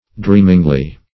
dreamingly - definition of dreamingly - synonyms, pronunciation, spelling from Free Dictionary Search Result for " dreamingly" : The Collaborative International Dictionary of English v.0.48: Dreamingly \Dream"ing*ly\, adv.
dreamingly.mp3